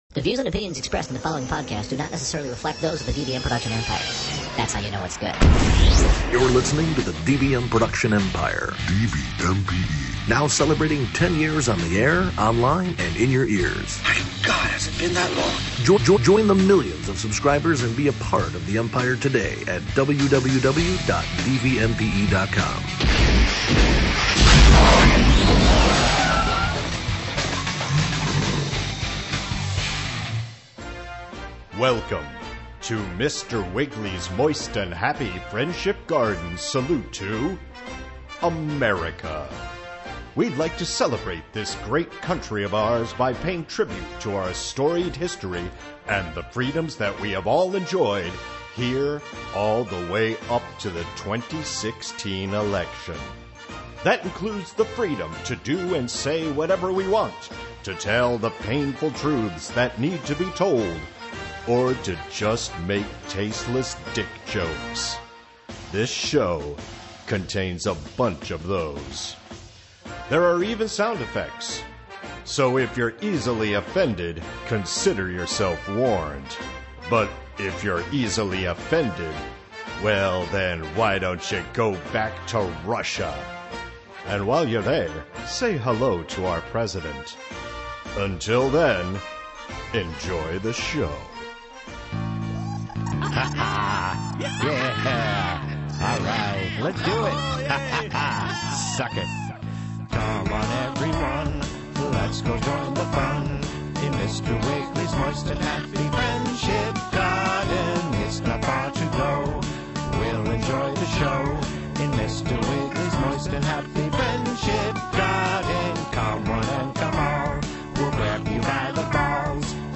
Wiggly visits the US Treasury, some hard-to-pronounce locations, and Uncle Sam. We hear about some important points in history, and Pop Machine gives us Teddy Roosevelt with a punk-rock flavor.